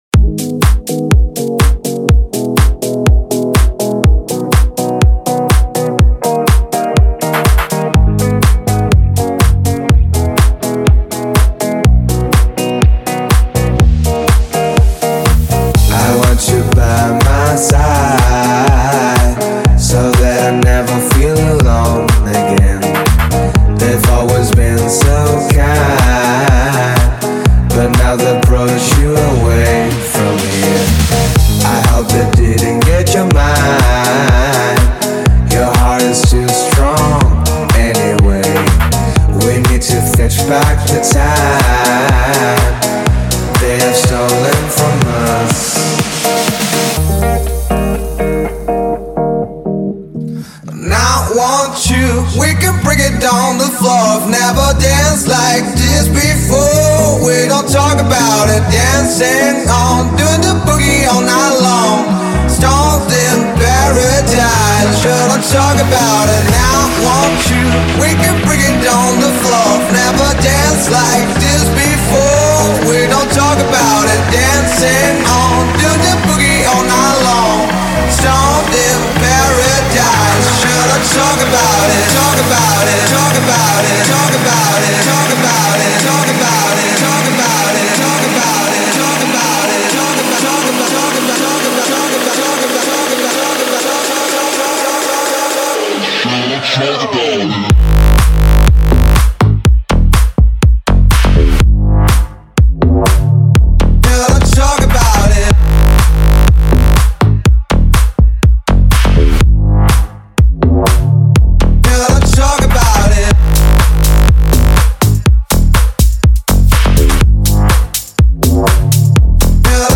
1h de Mix !